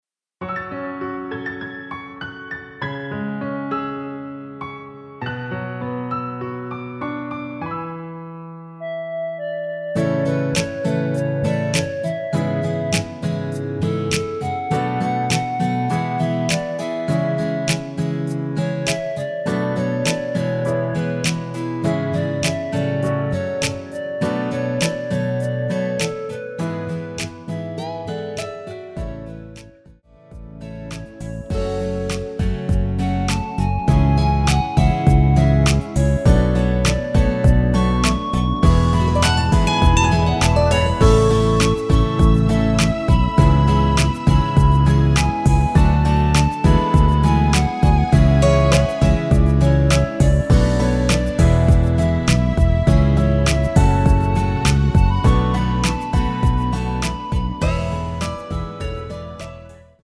원키에서(+6)올린 멜로디 포함된 MR입니다.
여성분 혼자서 부르실수 있는 키로 멜로디 포함하여 제작 하였습니다.(미리듣기 참조)
앞부분30초, 뒷부분30초씩 편집해서 올려 드리고 있습니다.
중간에 음이 끈어지고 다시 나오는 이유는